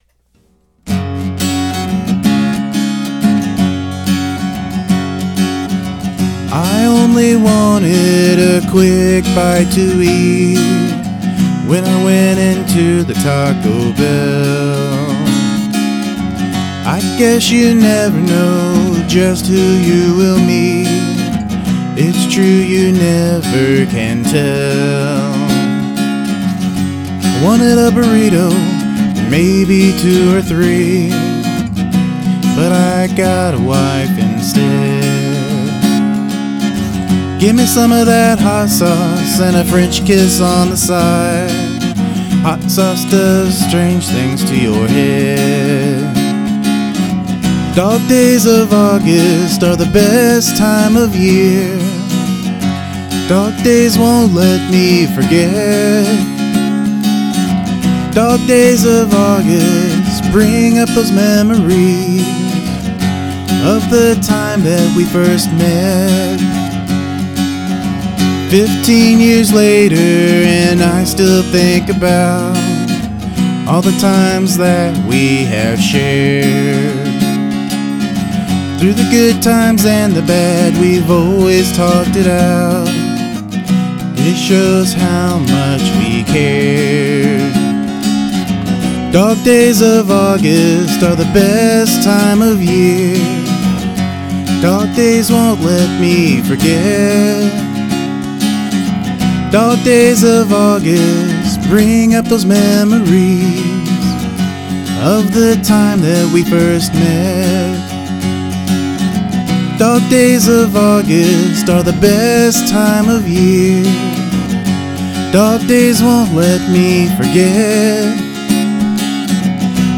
Good vibes!
A man, his guitar, and a song about his wife and a burrito - what else could you ask for?
Cool lyrics, voice, and strumming guitar!